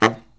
LOHITSAX09-L.wav